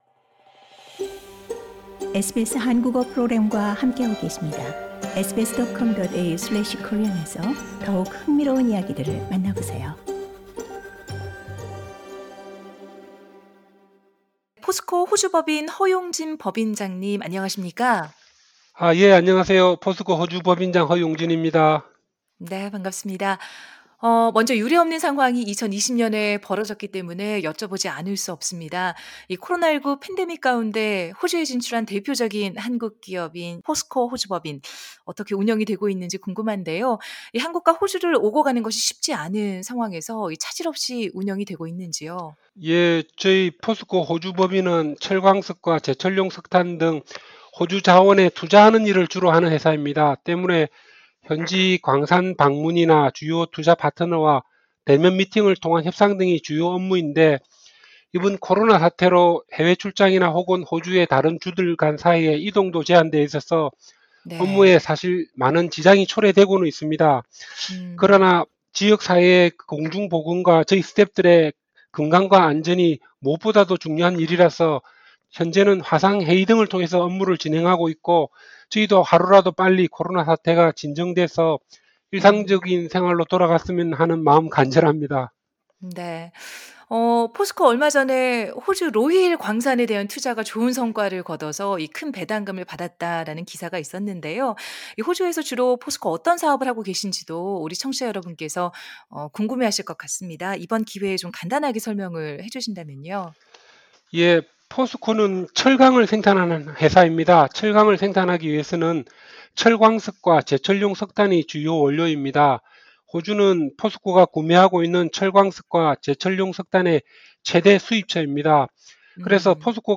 The full interview in Korea is available on the podcast above.